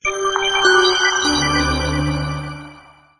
Index of /phonetones/unzipped/LG/KH1200/Event sounds
Power Off.aac